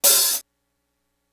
01 hat open hit.wav